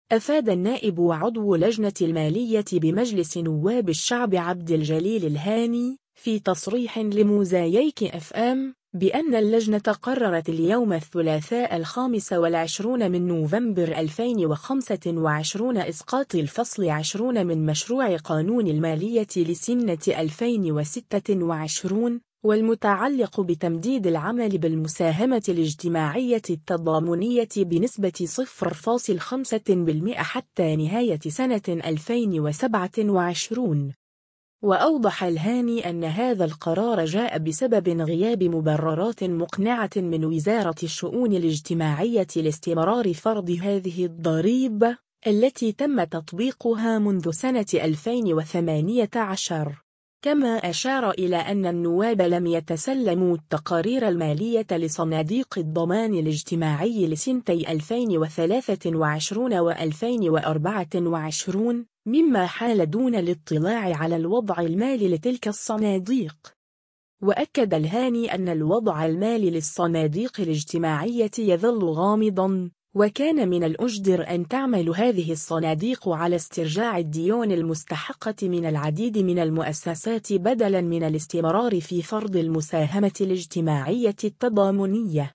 أفاد النائب وعضو لجنة المالية بمجلس نواب الشعب عبد الجليل الهاني، في تصريح لموزاييك إف إم، بأن اللجنة قررت اليوم الثلاثاء 25 نوفمبر 2025 إسقاط الفصل 20 من مشروع قانون المالية لسنة 2026، والمتعلق بتمديد العمل بالمساهمة الاجتماعية التضامنية بنسبة 0.5% حتى نهاية سنة 2027.